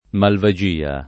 Malvagia [ malva J& a ] → Malvasia